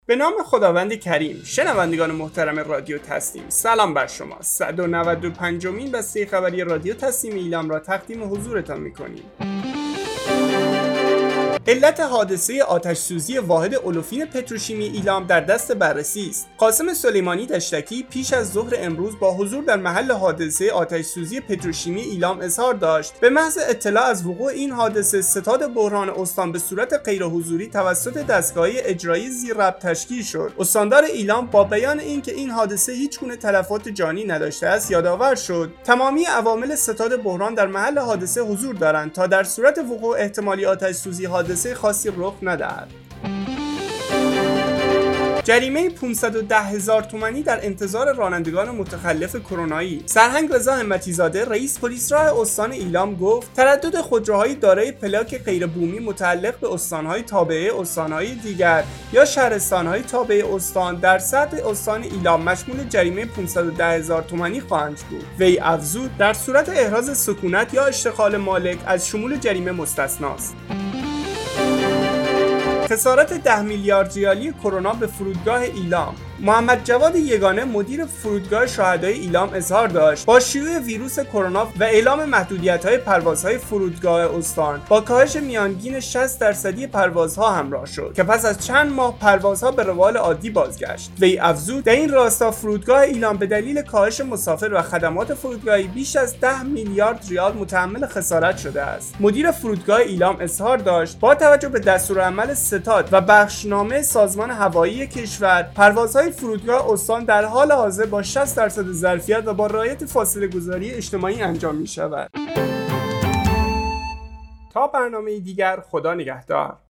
گروه استان‌ها - آخرین و مهمترین اخبار استان ایلام در قالب بسته خبری